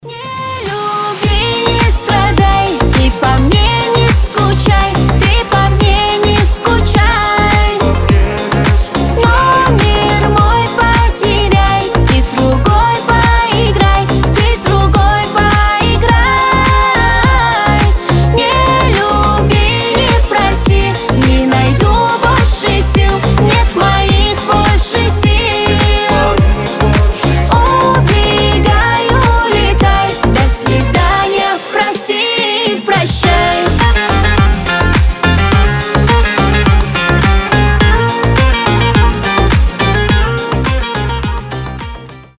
• Качество: 320, Stereo
красивые
женский голос
восточные